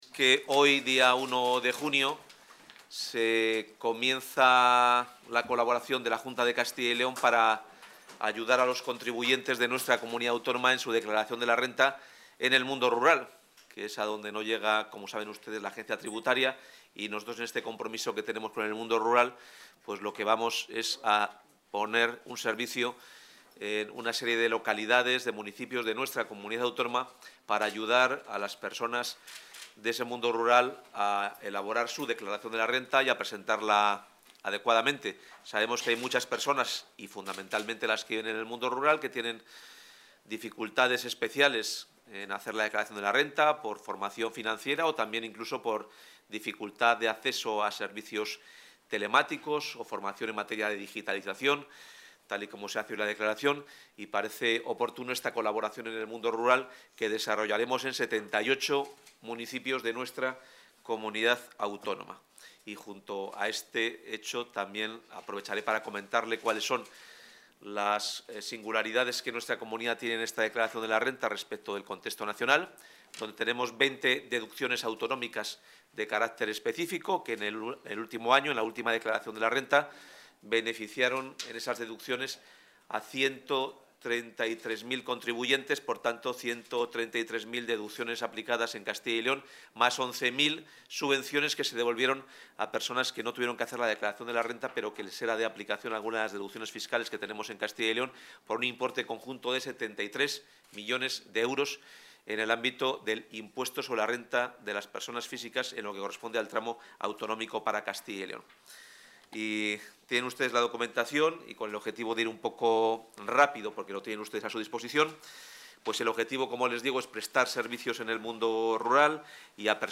Intervención del consejero de Economía y Hacienda.